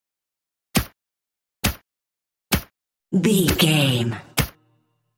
Pistol Firing with Silencer 02 | VGAME
Filled with 5 sounds(44/16 wav.) of Pistol Firing(Five single shots) with silencer.
Sound Effects
Adobe Audition, Zoom h4
muted
silenced